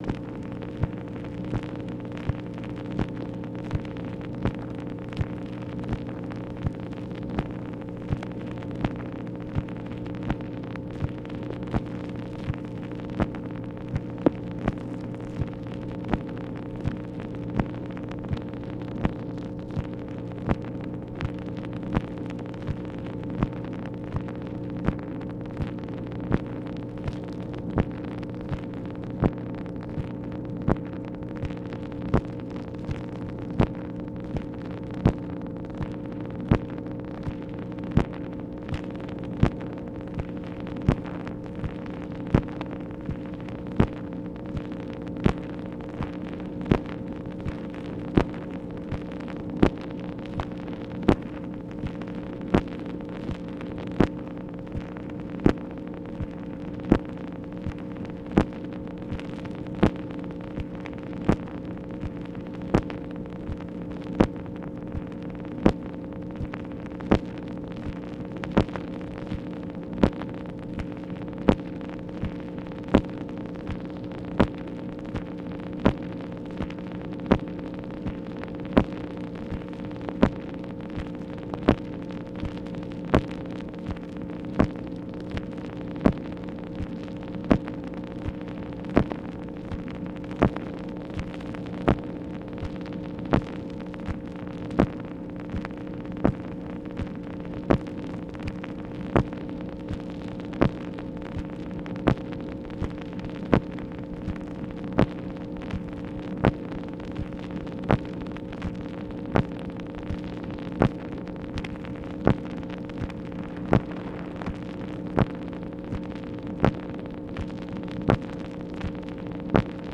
MACHINE NOISE, August 24, 1964
Secret White House Tapes